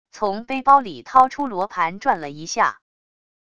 从背包里掏出罗盘转了一下wav音频